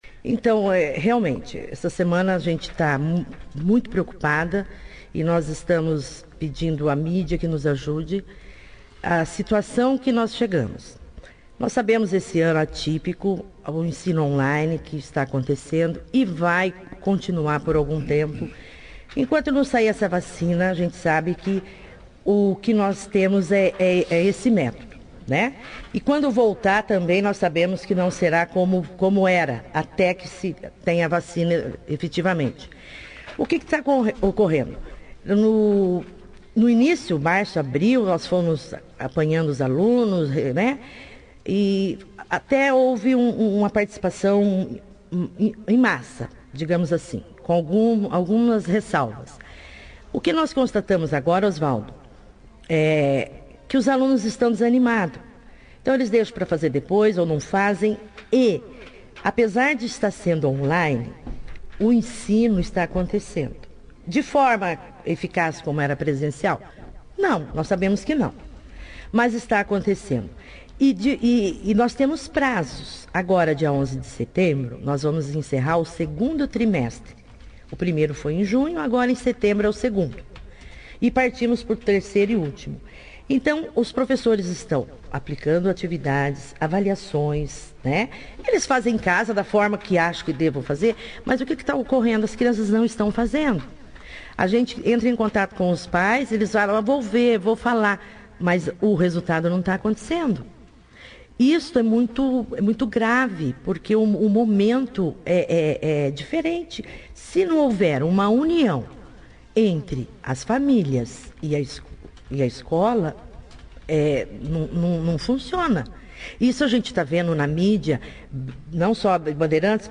participou da 2ª edição do jornal Operação Cidade desta quarta-feira